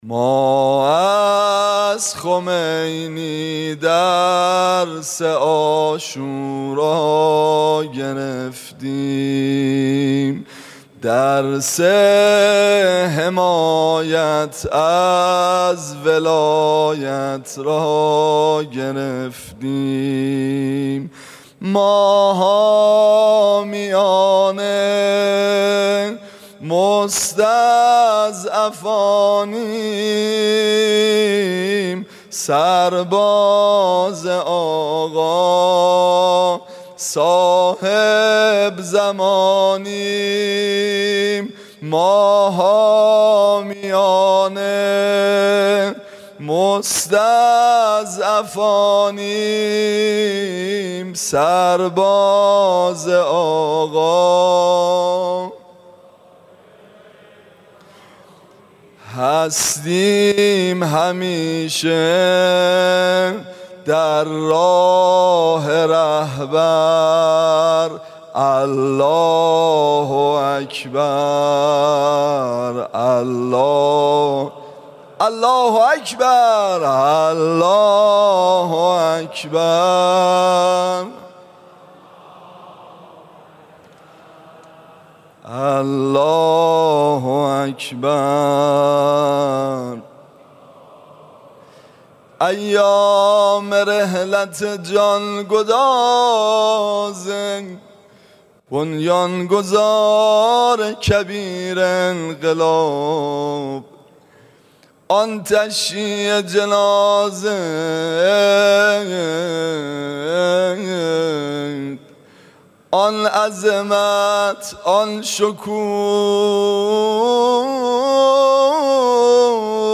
مداحی
پیش از خطبه های نماز جمعه تهران
عقیق: امروز که در سالروز قیام 15 خرداد هستیم و نیز در ایام ولادت با سعادت حضرت حجت بن الحسن العسکری (روحی و أرواح العالمین لتراب مقدمه الفداه) و سالگرد ارتحال ملکوتی بنیانگذار جمهوری اسلامی، حضرت روح الله الموسوی الخمینی (رحمةالله علیه) قرار داریم، نماز عبادی سیاسی جمعه تهران با امامت آیت الله محمد امامی کاشانی در دانشگاه تهران برگزار شد.
پیش از اذان ظهر و شروع خطبه ها، به مناسبت های مطابق با این ایام، مداح اهل بیت